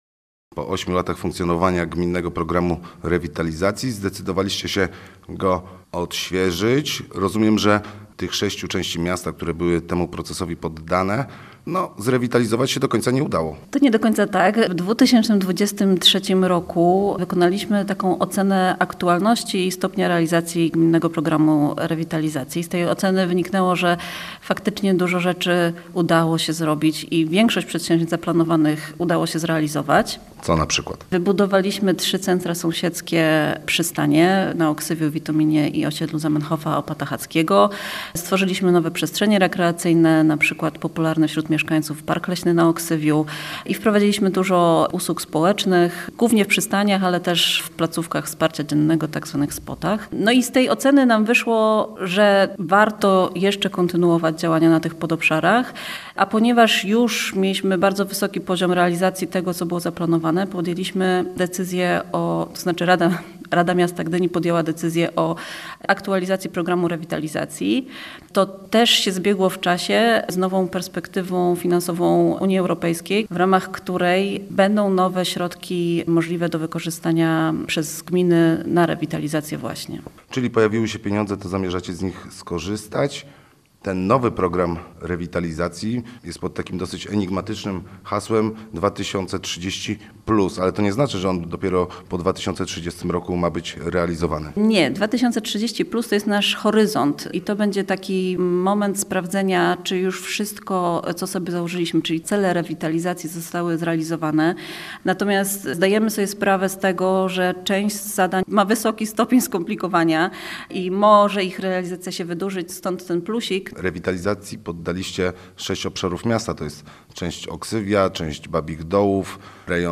Posłuchaj rozmowy naszego dziennikarza